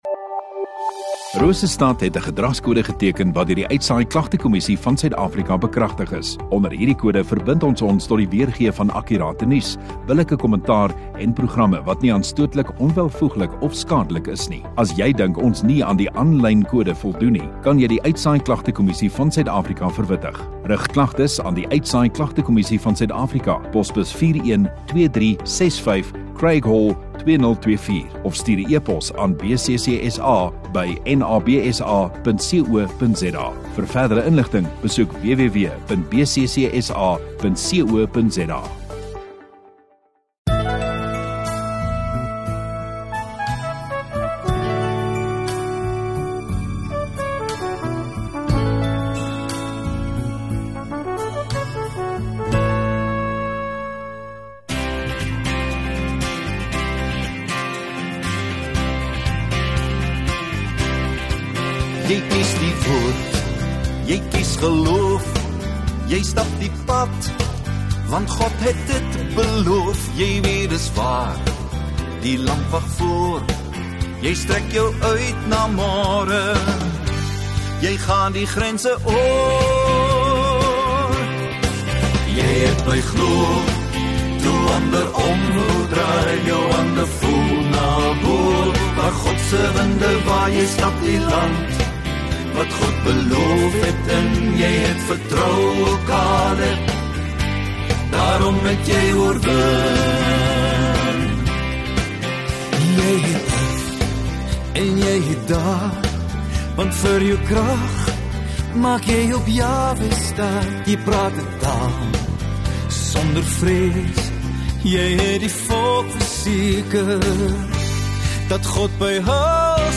6 Oct Sondagaand Erediens